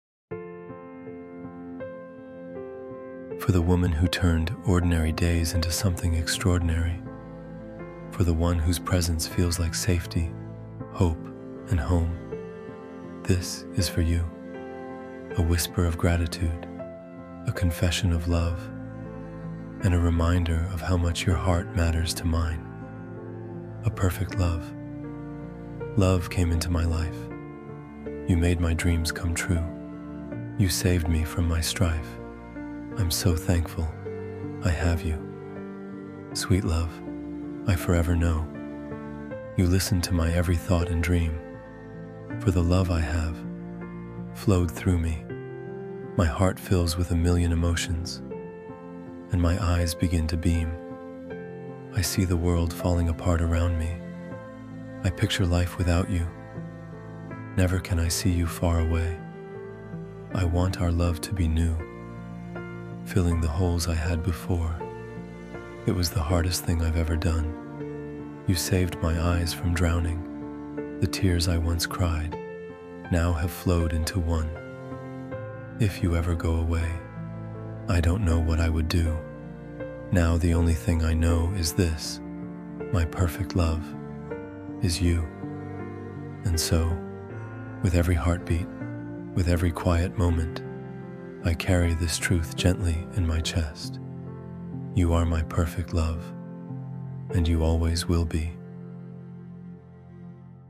A-Perfect-Love-–-Romantic-Spoken-Word-Poem.mp3